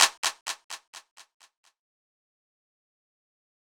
Blocka Clap.wav